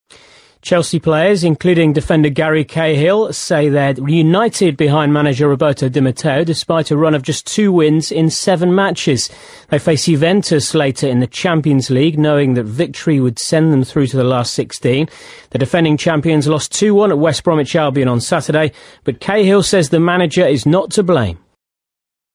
【英音模仿秀】欧冠切尔西客场挑战尤文晋级生死战提前上演 听力文件下载—在线英语听力室